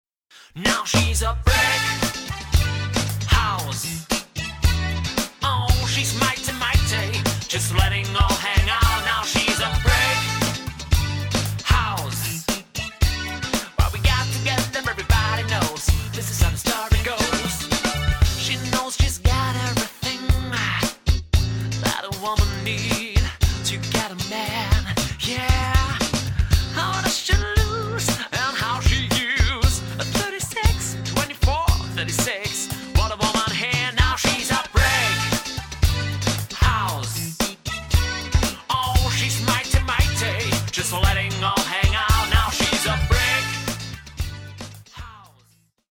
Sängerin, Sänger, Gitarre/Gesang, Bass/Keyboard, Schlagzeug